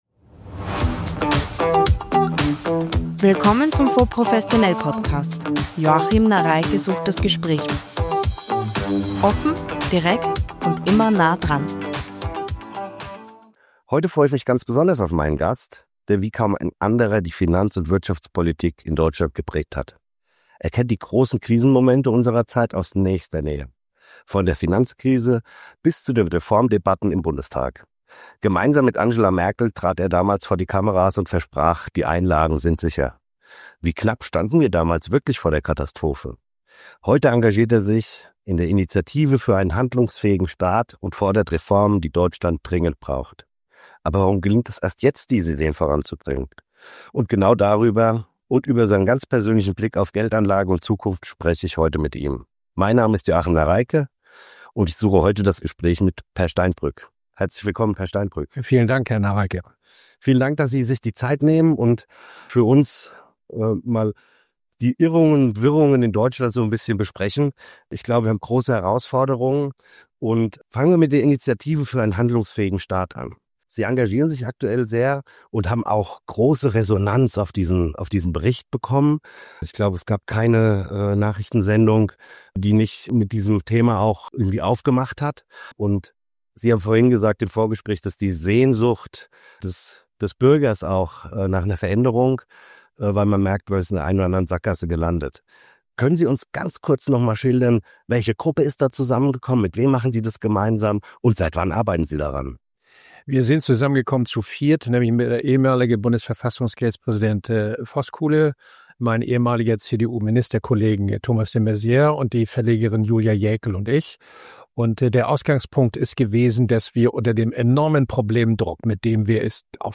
das Gespräch mit Peer Steinbrück ~ FONDS professionell PODCAST
Im neuen FONDS professionell Podcast spricht Ex-Bundesfinanzminister Peer Steinbrück über Reformstau, Staatsversagen – und warum Deutschland seine "bequeme Gegenwart" endlich verlassen muss, um wieder handlungsfähig zu werden.